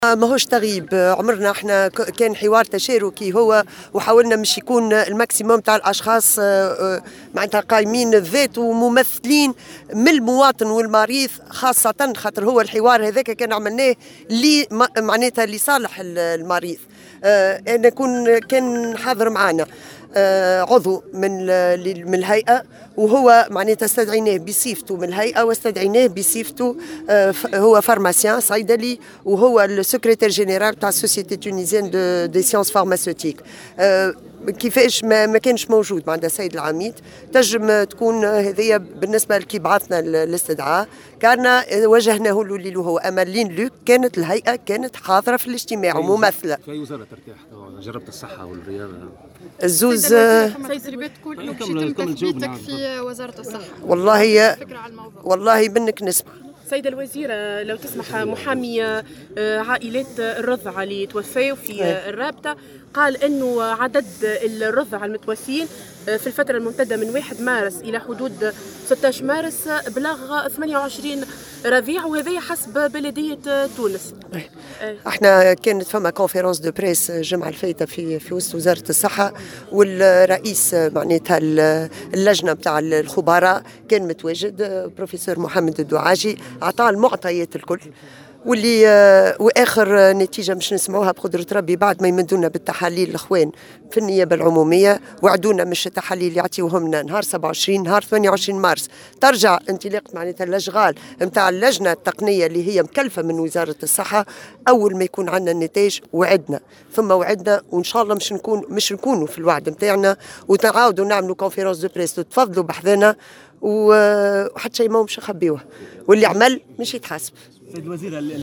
وأضافت في تصريح لموفدة "الجوهرة أف أم" على هامش زيارة أدتها اليوم إلى سوسة ، انه بعد مد النيابة العمومية الوزارة بالتحاليل يوم 27 مارس ستستأنف اللجنة التقنية التي كلفتها وزارة الصحة أشغالها في اليوم الموالي ليتم في وقت لاحق عقد ندوة صحفية لاعلان نتائج التحقيق بشكل الرسمي.